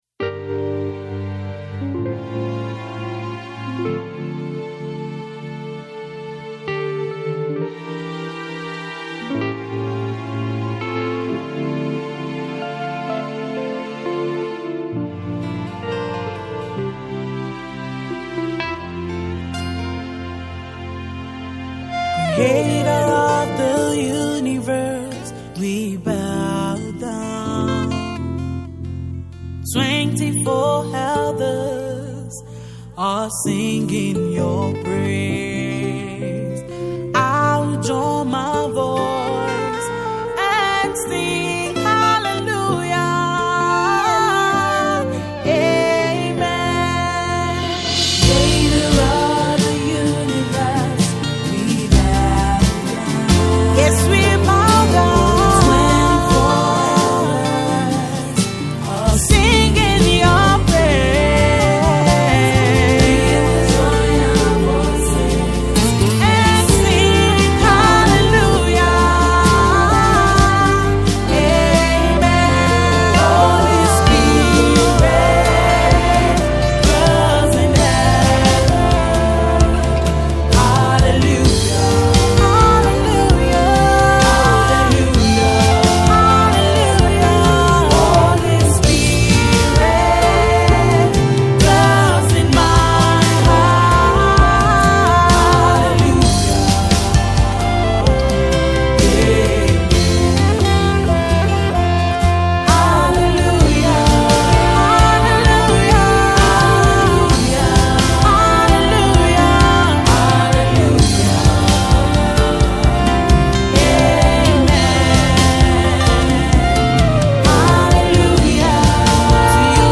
is a versatile gospel singer